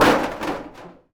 metal_sheet_impacts_07.wav